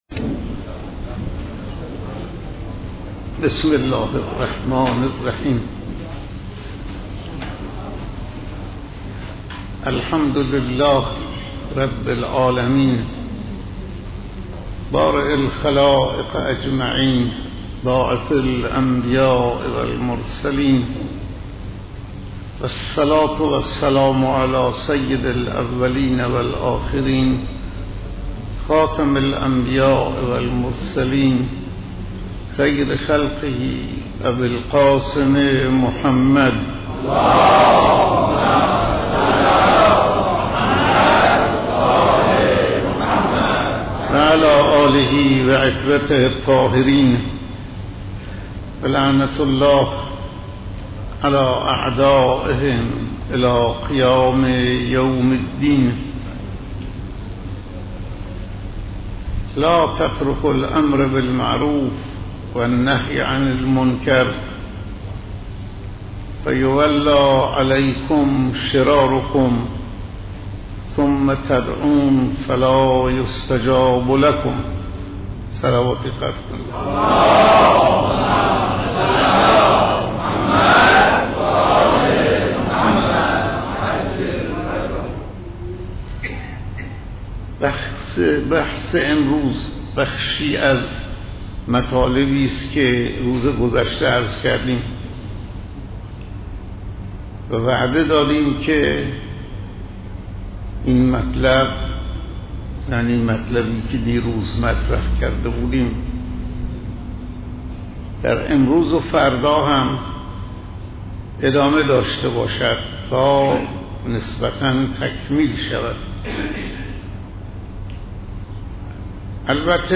سخنرانی روز ۲۰ رمضان - صوتی :: پایگاه خبری مسجد حضرت آیت الله شفیعی اهواز
‌ سخنرانی حضرت آیت الله شفیعی روز ۲۰ ماه رمضان (سه شنبه شانزدهم تیرماه)